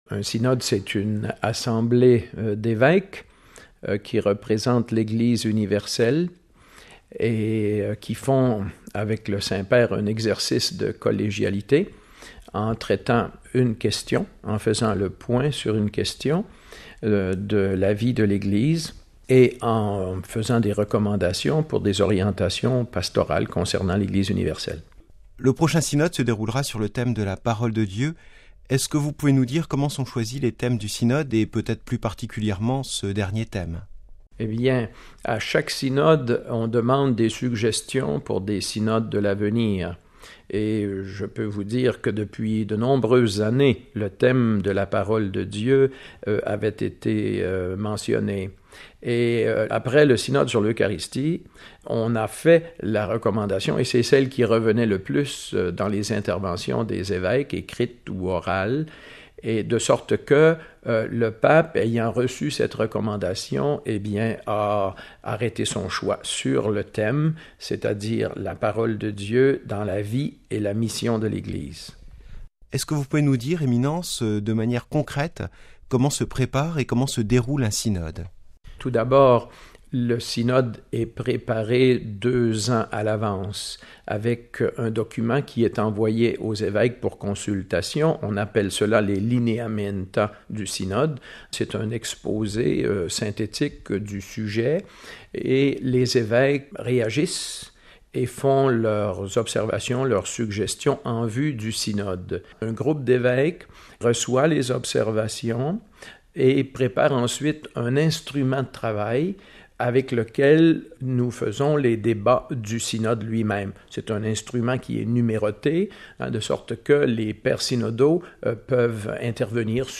Le cardinal Marc Ouellet, archevêque de Québec et rapporteur général pour le prochain synode, nous présente ce qu’est le synode, son déroulement, et les objectifs de cette rencontre.